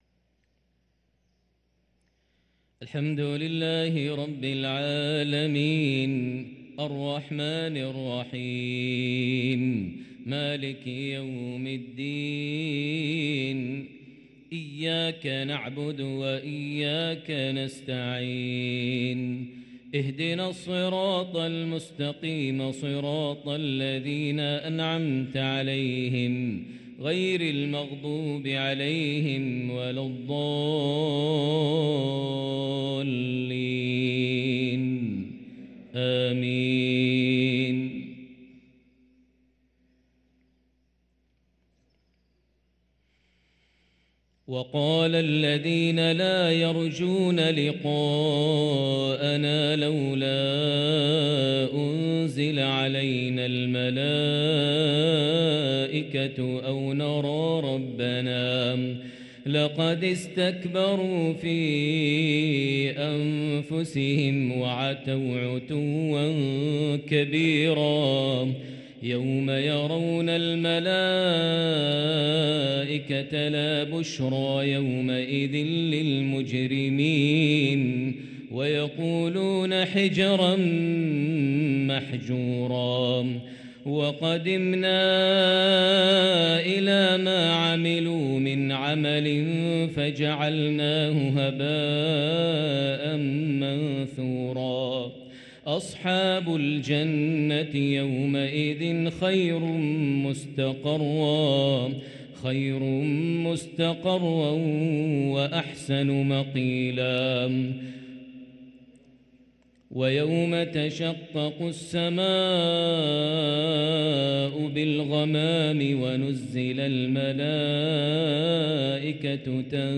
صلاة المغرب للقارئ ماهر المعيقلي 23 جمادي الآخر 1444 هـ
تِلَاوَات الْحَرَمَيْن .